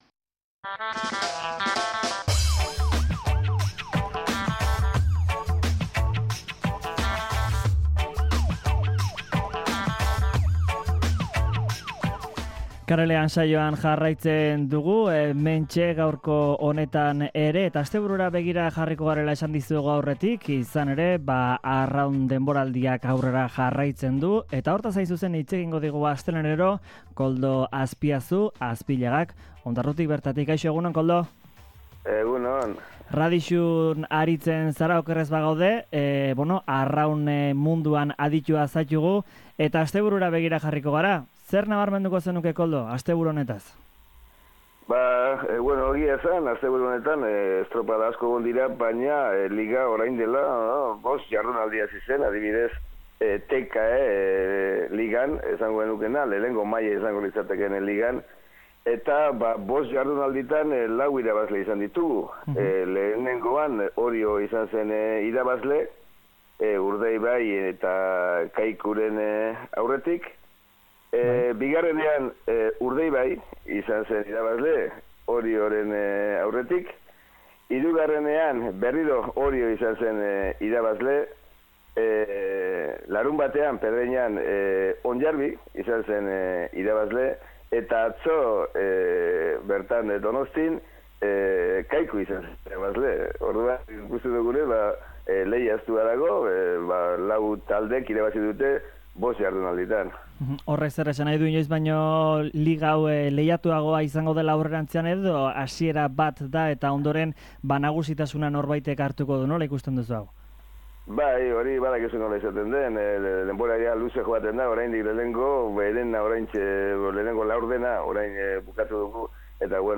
Udako Elkarrizketak